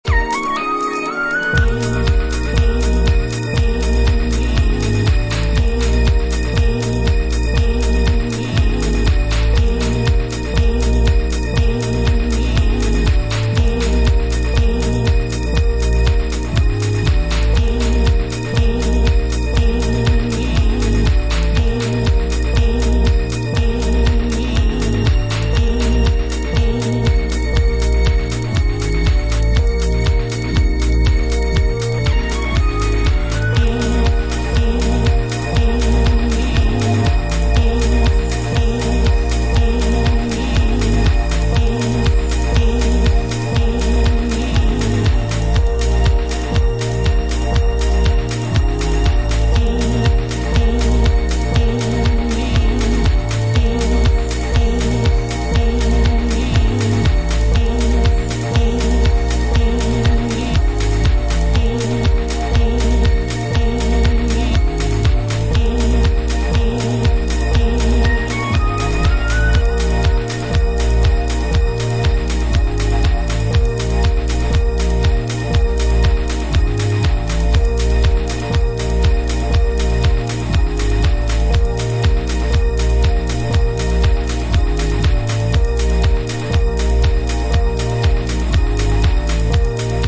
Organic, raw and real house music with a warm groove!